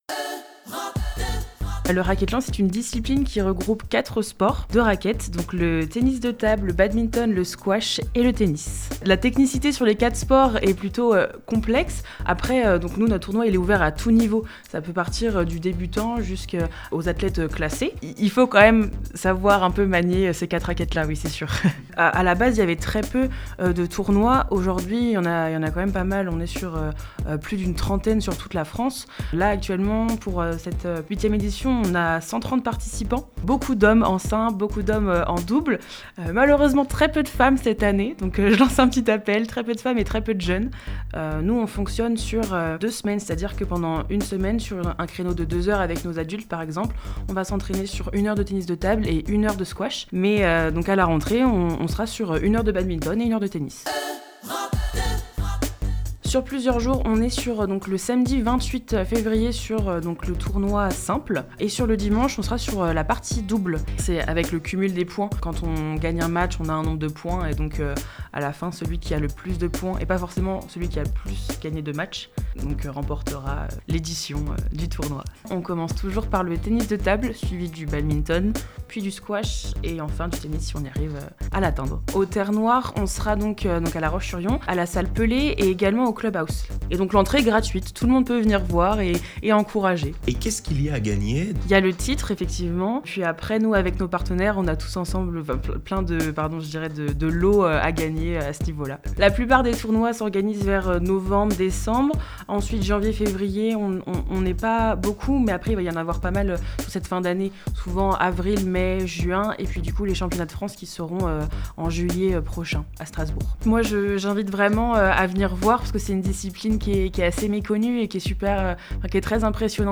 Les précisions de